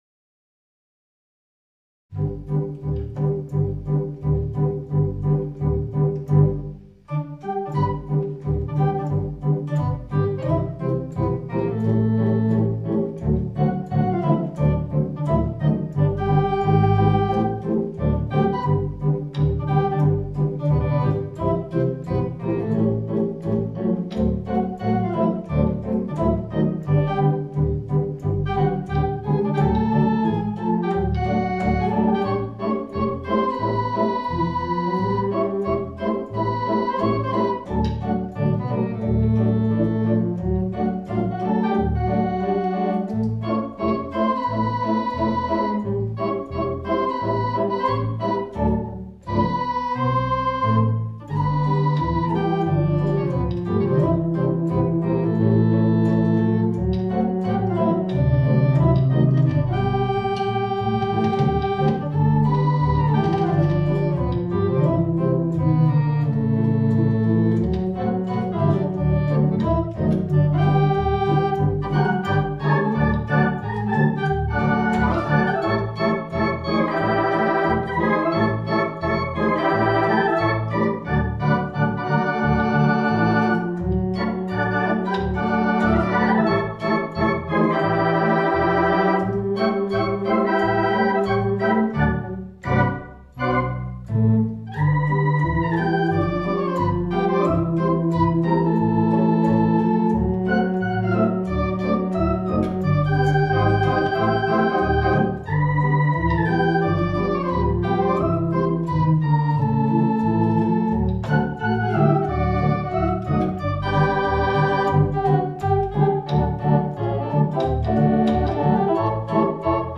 Mighty Conn 640 Vacuum Tube Theatre Organ.